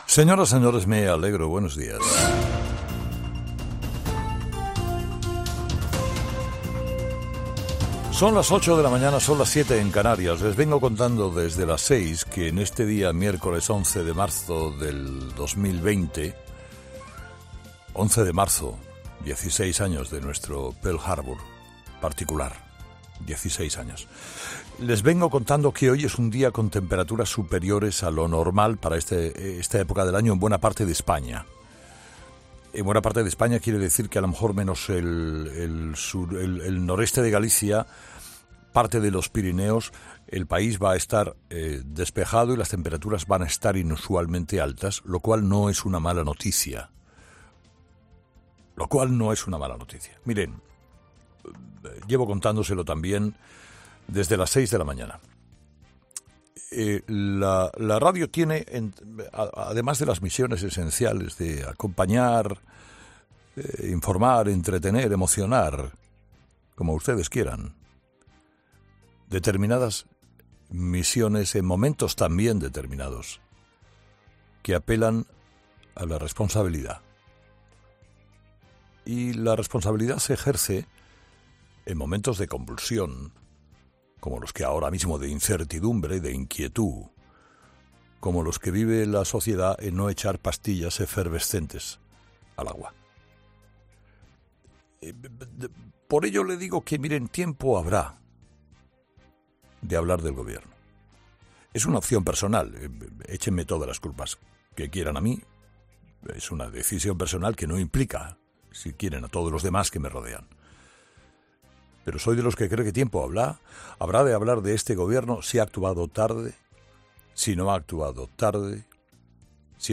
ESCUCHA AQUÍ EL EDITORIAL COMPLETO DE HERRERA